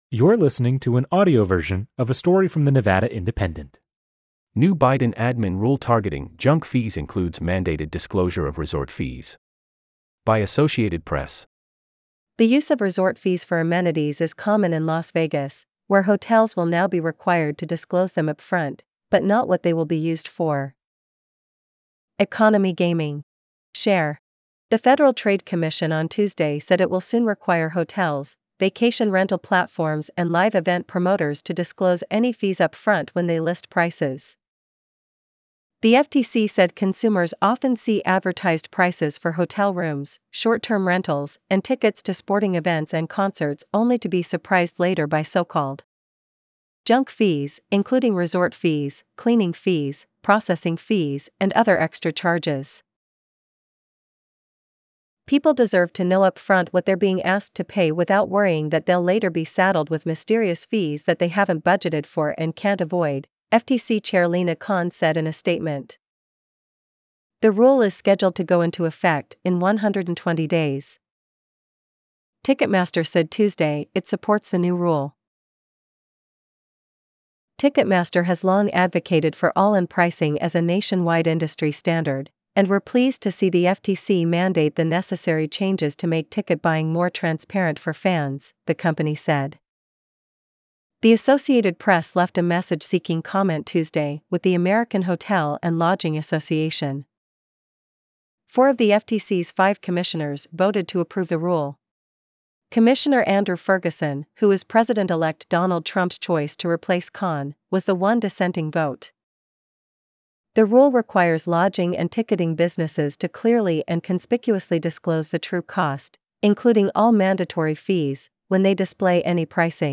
segment_narration_zfFyTc7-wIY6x7tp9Hq-3.wav